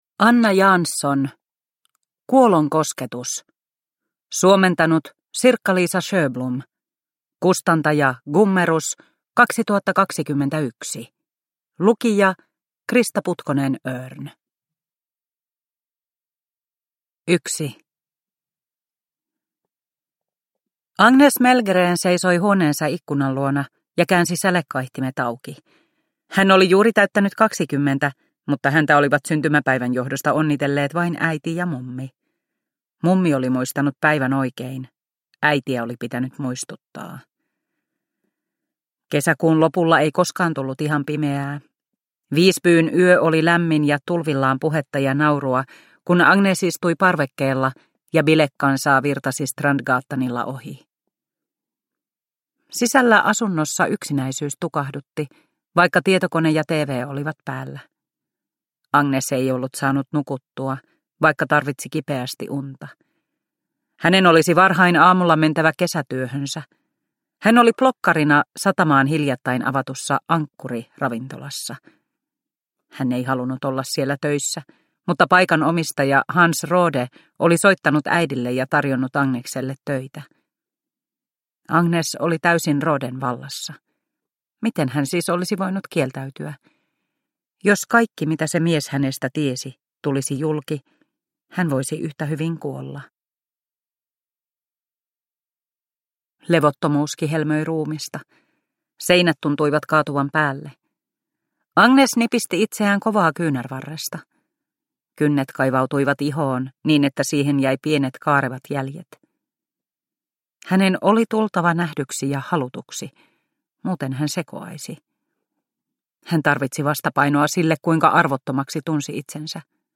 Kuolon kosketus – Ljudbok – Laddas ner